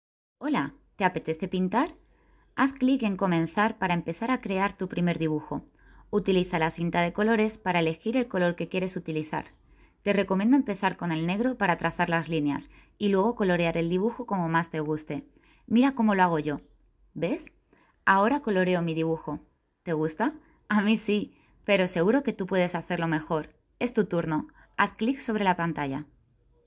kastilisch
Sprechprobe: eLearning (Muttersprache):